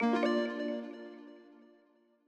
Longhorn 8 - Unlock.wav